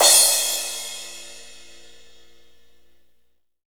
Index of /90_sSampleCDs/Northstar - Drumscapes Roland/DRM_AC Lite Jazz/CYM_A_C Cymbalsx